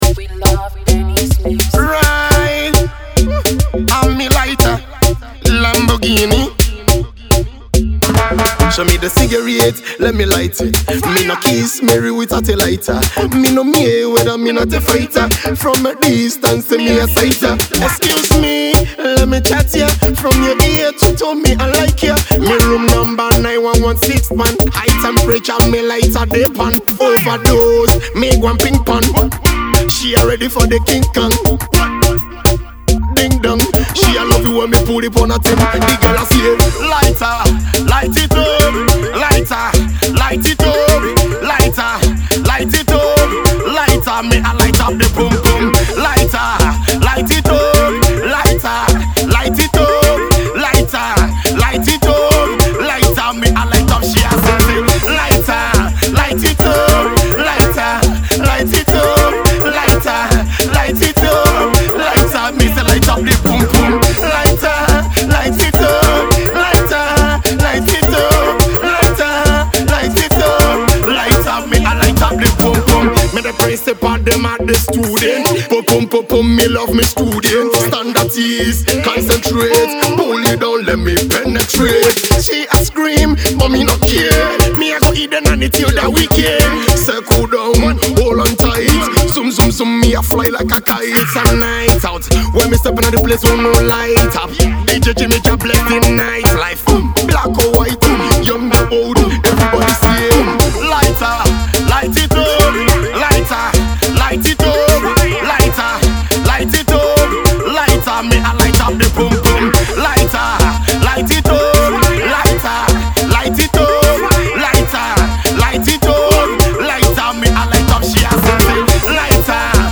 sublime tune